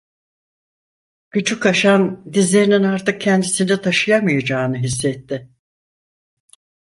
Pronunciado como (IPA)
[ɑɾˈtɯk]